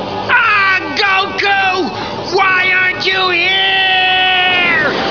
Krillun yelling "Ah, Goku! Why aren't you here!"
krillunyell.wav